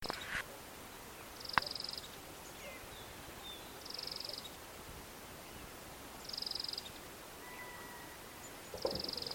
Rayadito (Aphrastura spinicauda)
Nombre en inglés: Thorn-tailed Rayadito
Localidad o área protegida: Villa Pehuenia
Condición: Silvestre
Certeza: Observada, Vocalización Grabada
Rayadito.mp3